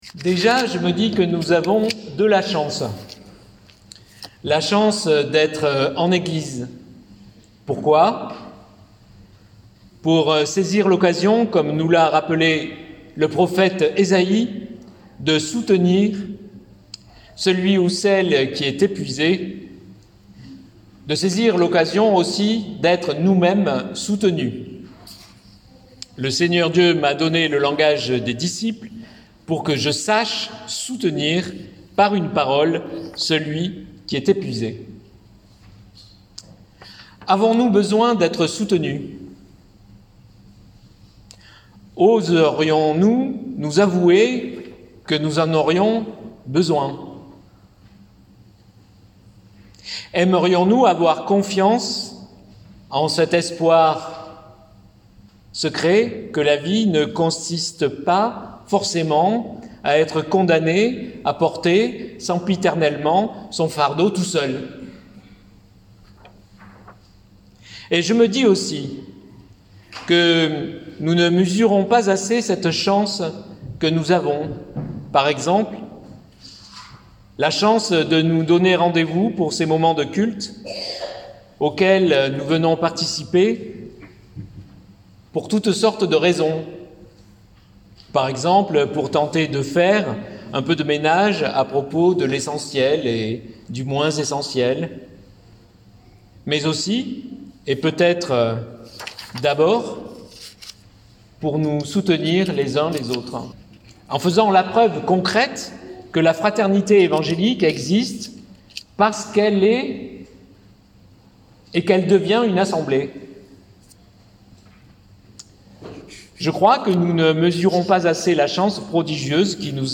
Prédication des rameaux 2022.mp3 (34.11 Mo)